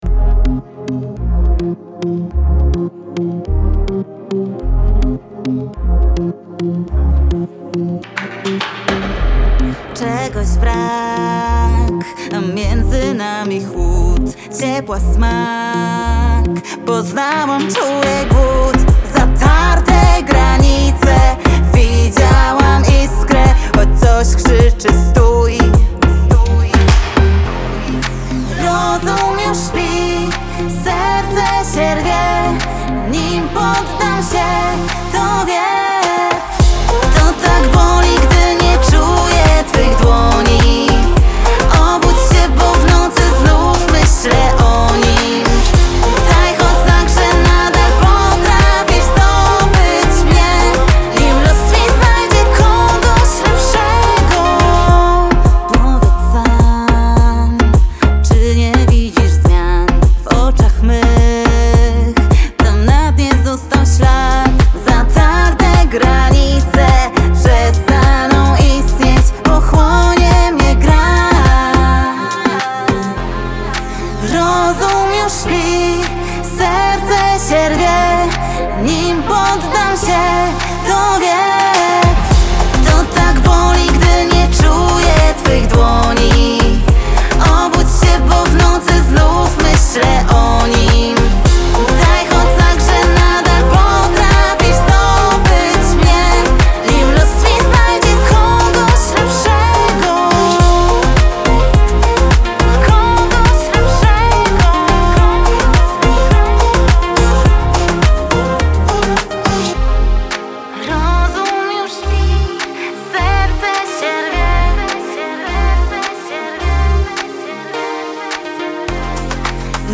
dance-pop
bardzo dobra produkcja wyraźnie zarysowany refren radiowy